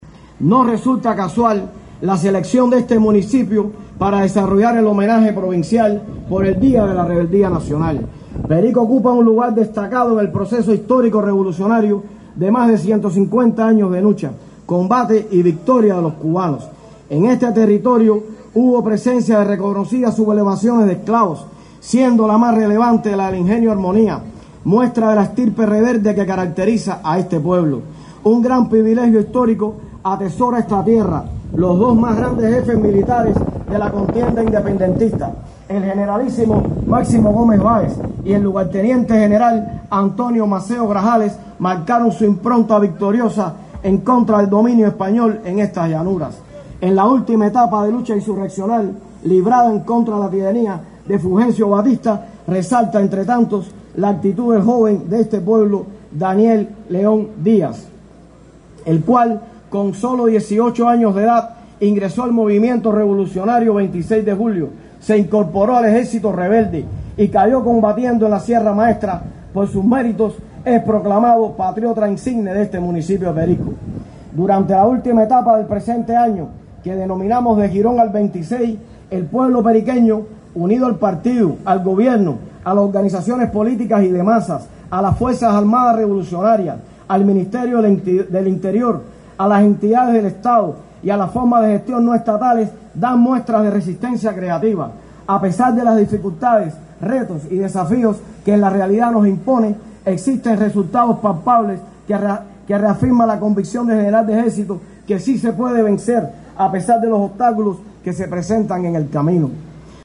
Méritos suficientes para que este territorio fuera la sede hoy del acto provincial por el Día de la Rebeldía Nacional.
Mario Sabines Lorenzo, diputado a la Asamblea Nacional del Poder Popular y primer secretario del Partido Comunista de Cuba en Matanzas, destacó la estirpe rebelde del municipio de Perico y su papel en el desarrollo de la provincia en la última etapa.